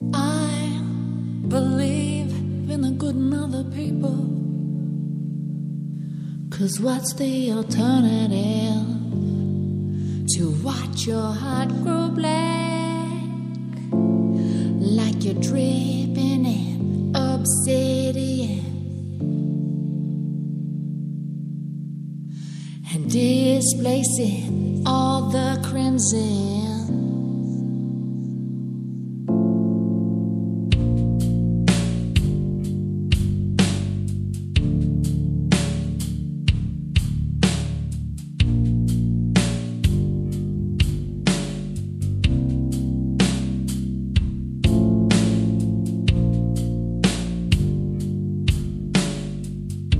kolekcja utworów z wokalem